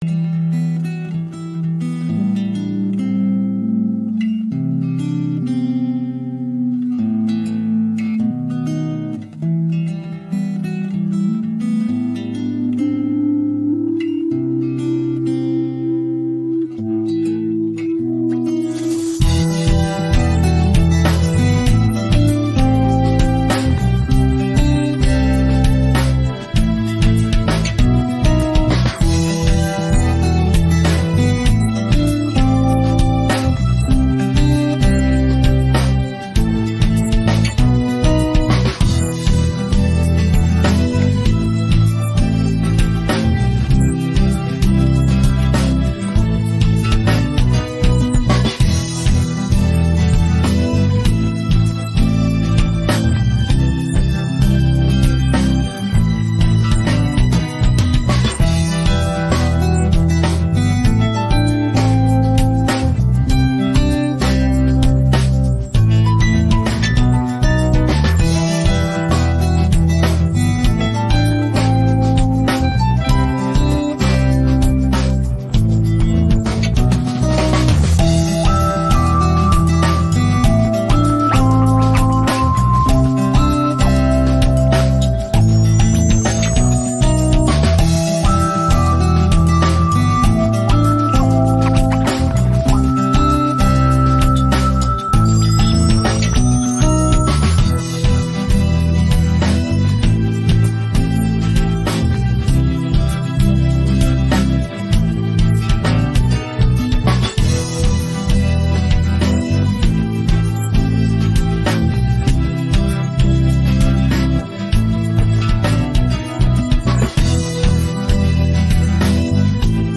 rock караоке 39